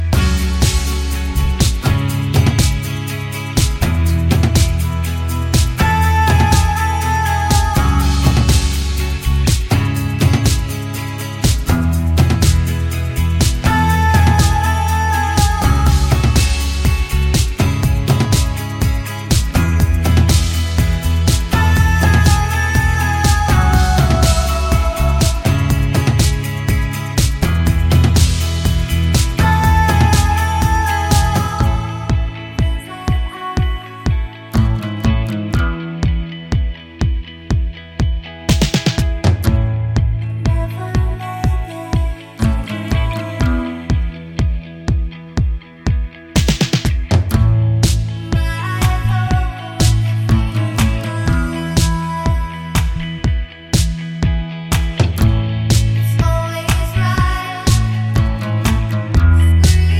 Cut Down Intro Pop (2010s) 3:53 Buy £1.50